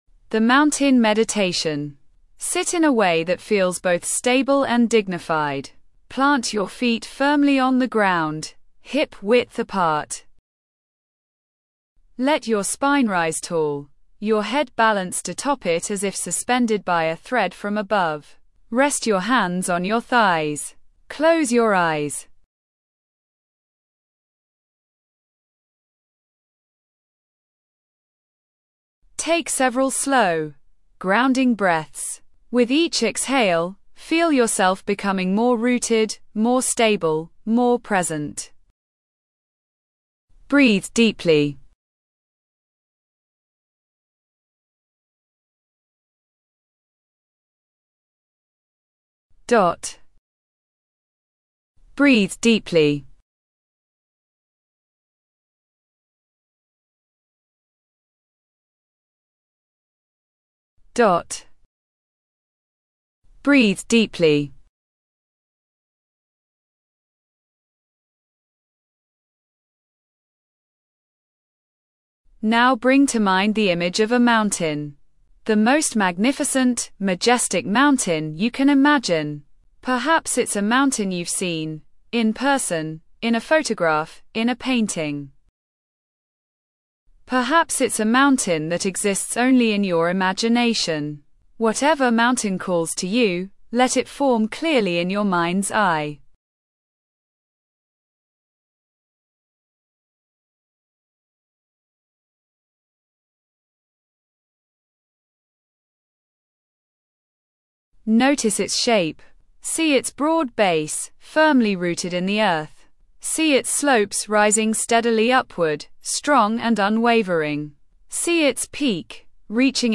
A visualization meditation where you embody the strength and stillness of a mountain. Excellent for building resilience during turbulent times.